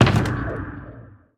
Minecraft Version Minecraft Version snapshot Latest Release | Latest Snapshot snapshot / assets / minecraft / sounds / block / enderchest / open.ogg Compare With Compare With Latest Release | Latest Snapshot
open.ogg